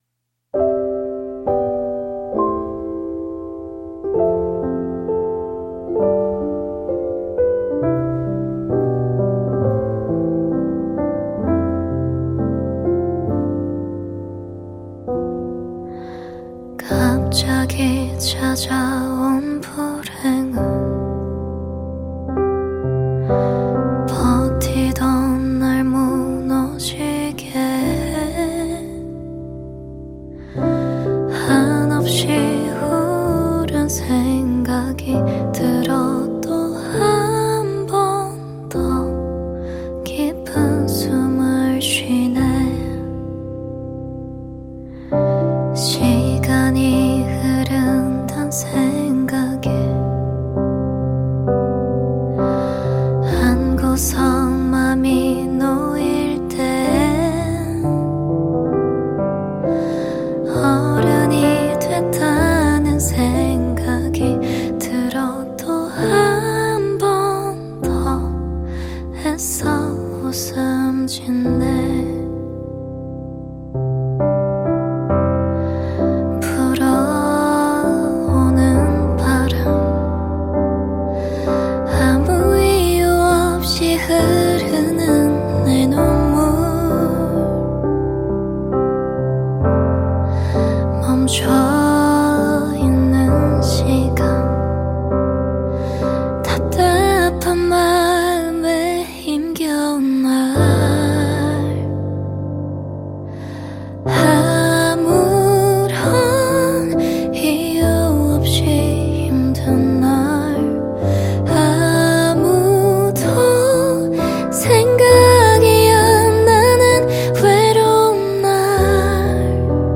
KPop Song